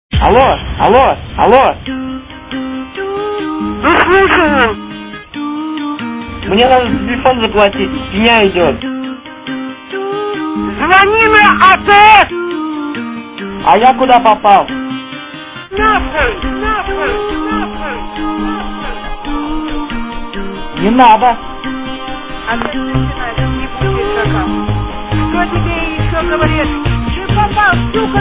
What's this dreamy song on the background?